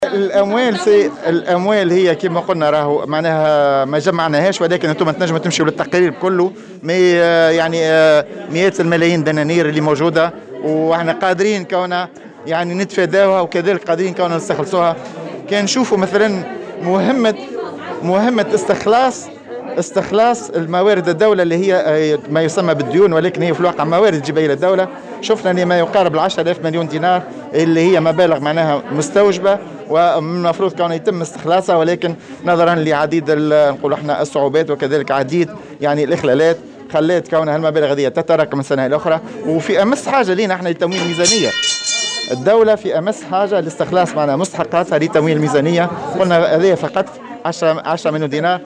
وأضاف خلال ندوة صحفية عقدتها المحكمة لتقديم تقريرها السنوي أن ذلك يعكس النقص الحاصل على مستوى حوكمة التصرف في المال العام، وفق تعبيره.